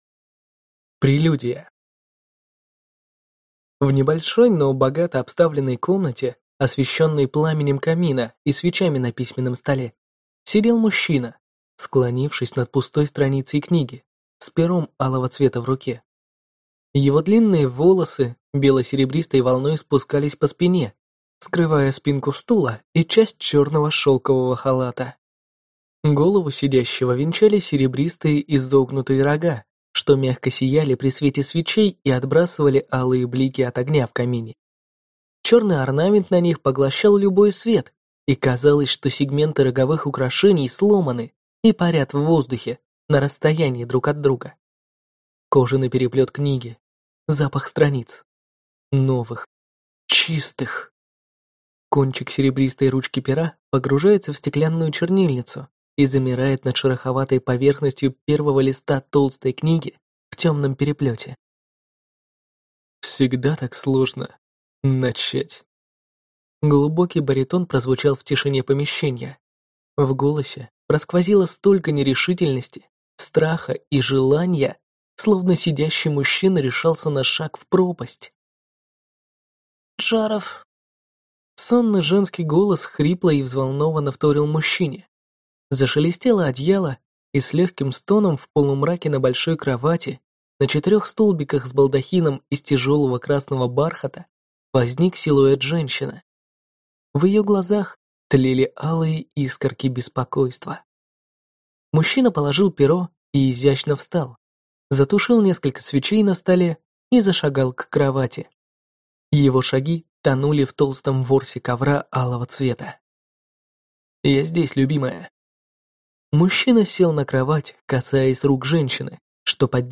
Аудиокнига Джареф. Кровь | Библиотека аудиокниг